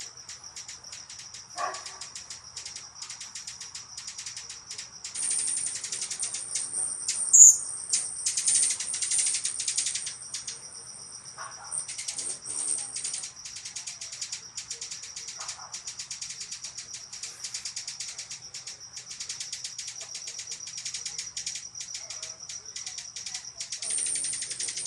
“Clicking” Hummingbird Calls
For whatever reason, tonight the hummingbird “clicking” songs outside in the back were particularly loud and energetic.
I tried recording the audio and video on my iPhone and was pleased with the result. I can clearly hear this high-pitched clicking, and can also hear the loud, lower-pitched “zoom” or “buzz” as they’re flitting around.
There’s a dog bark in there at one point early on and one of the house wrens sounding off because I’m standing near their nest (they’re up in the porch rafters over the spare refrigerator) but for the most part you can hear the clicking and the zooming quite clearly.
• So, in theory, shouldn’t I be able to rip the audio track off of the video on my iPhone, upload that audio file to my desktop, use one of my audio editing programs to re-adjust the equilization to lower the background hum of the bass that the iPhone wants to put in there and bring back out the high-frequency sounds from the hummingbirds?
It’s a 25 second clip, with the dog barking at 1.6 seconds and the house finch sounding off at 7.2 seconds. All throughout there’s constant “clicking,” with the “zoomy buzzing” at about 5 to 10 seconds, 12 seconds, and 24 seconds.
img_4048-eq-adjusted.mp3